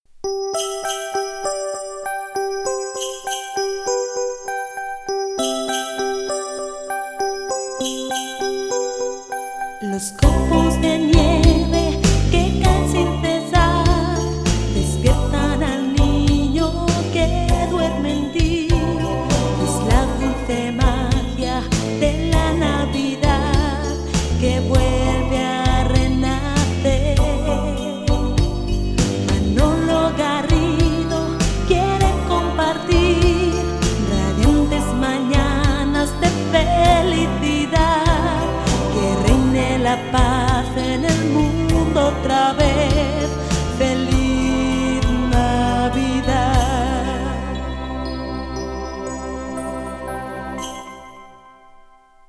Nadala cantada del programa
Entreteniment